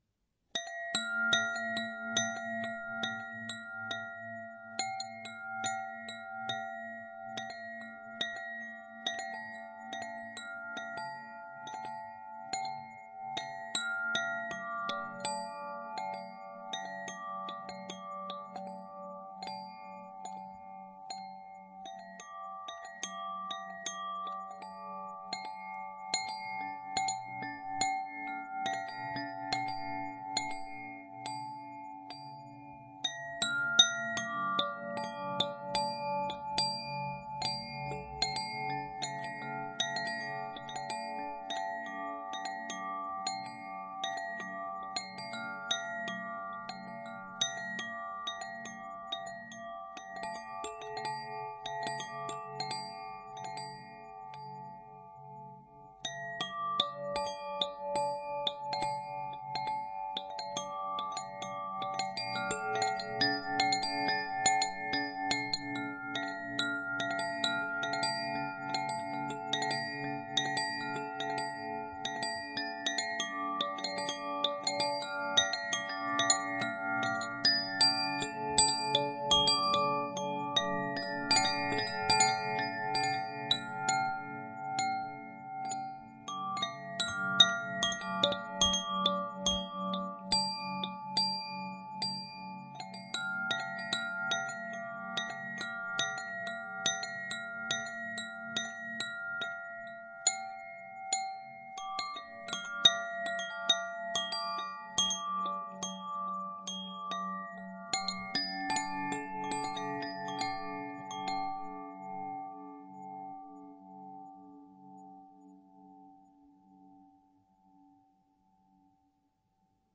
仪器 " 甲子风铃
描述：带有八个金属色调的风铃由竹共振管包围。
Tag: 风渐ES 冥想 日本 自然的音场 风铃 挺括 风铃